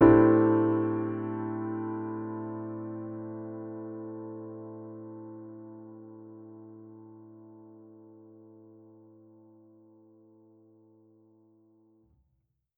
Index of /musicradar/jazz-keys-samples/Chord Hits/Acoustic Piano 1
JK_AcPiano1_Chord-Am11.wav